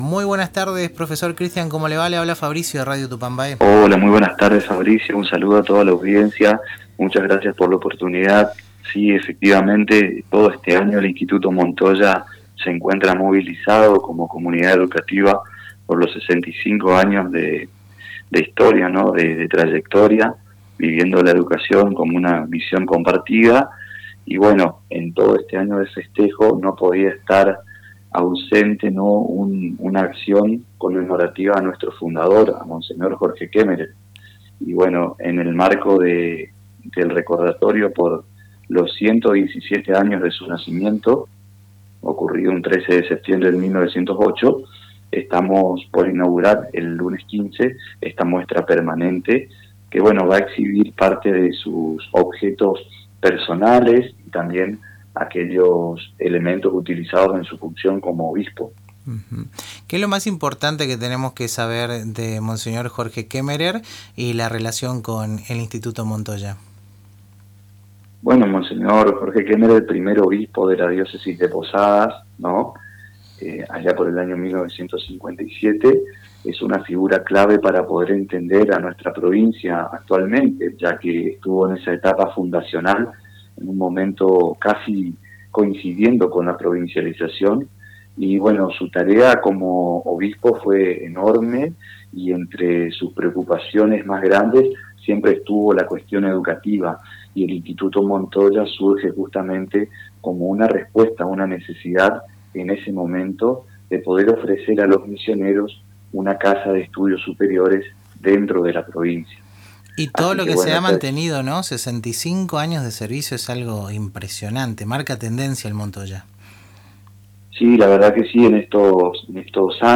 en diálogo con El Ritmo Sigue por Radio Tupambaé.